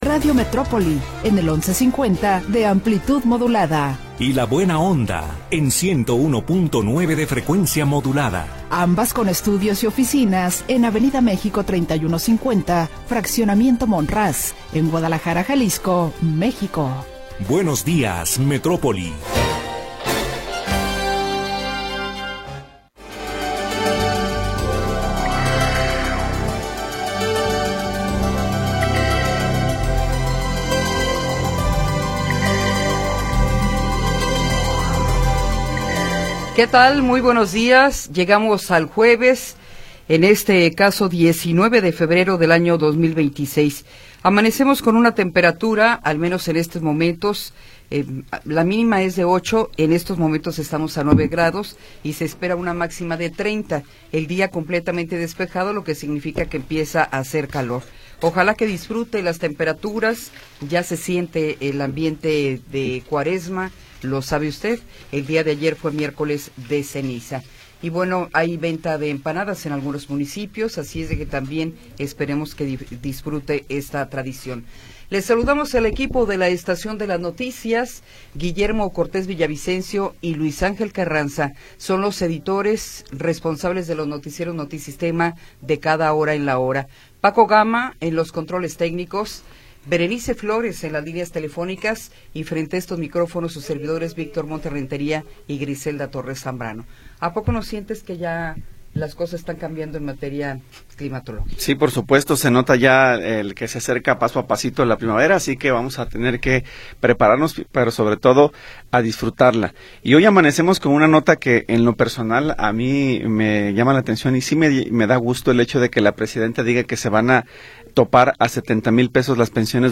Información oportuna y entrevistas de interés